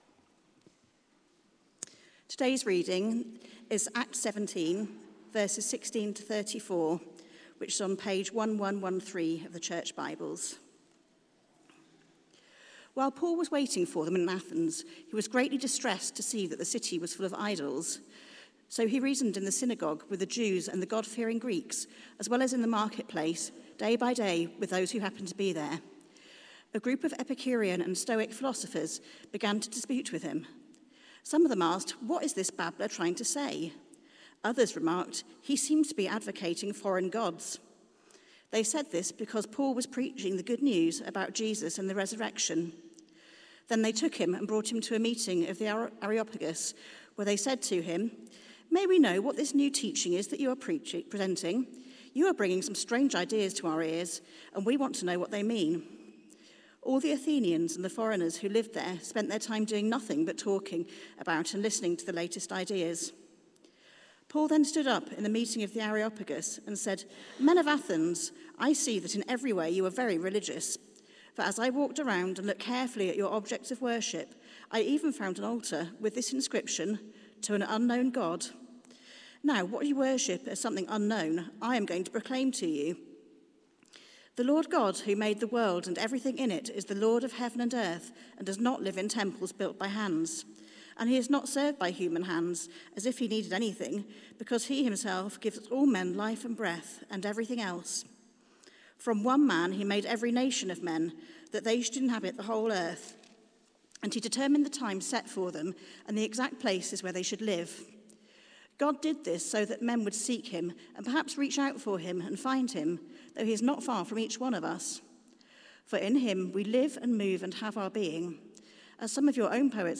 Acts: Mission Unstoppable! Theme: The Gospel Reaches Greece Sermon Search: